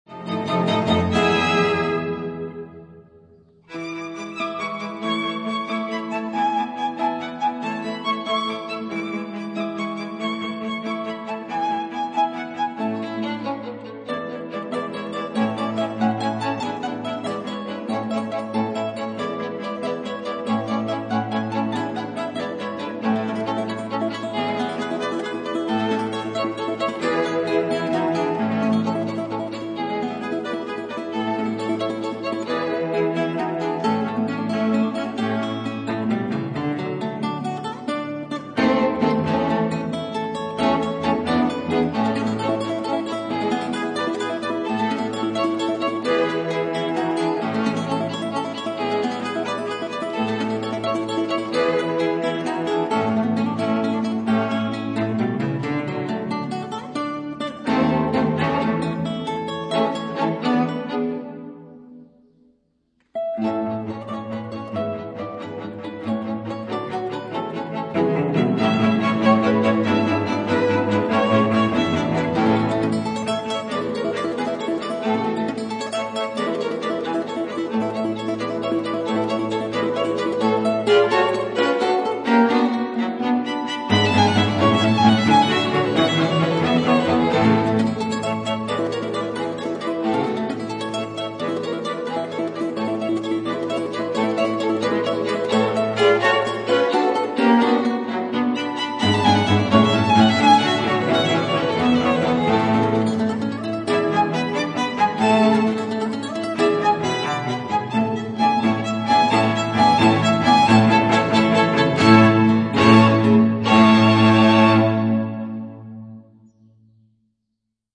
Allegretto from Solo op. 207 n. 2 (live) sample 1’57’’ (guitar and string trio)